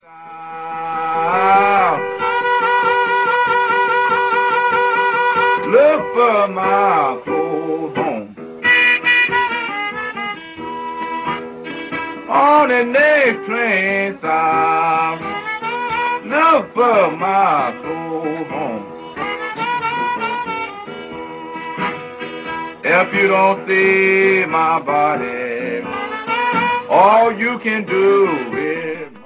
вокал, губная гармоника
гитара